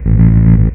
FINGERBSS3-L.wav